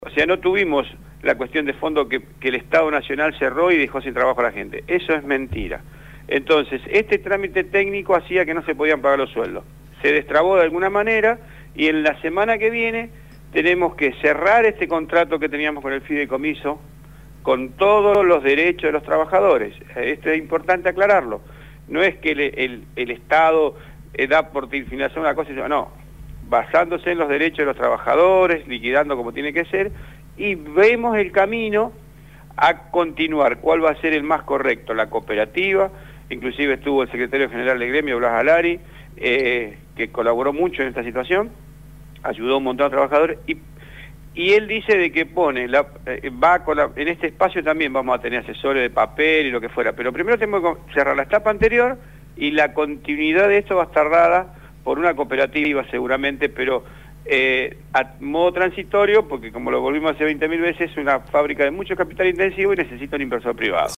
Los compañeros de «Patas Cortas» entrevistaron